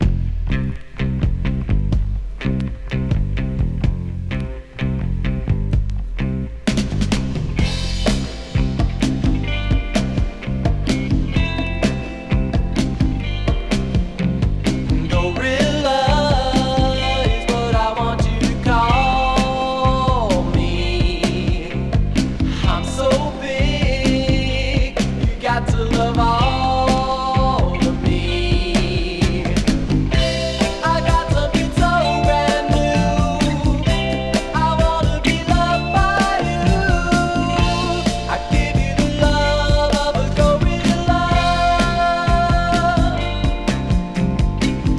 Rock, Garage　USA　12inchレコード　33rpm　Stereo